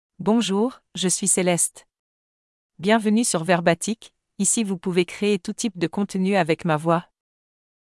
CelesteFemale French AI voice
Celeste is a female AI voice for French (France).
Voice sample
Listen to Celeste's female French voice.
Female
Celeste delivers clear pronunciation with authentic France French intonation, making your content sound professionally produced.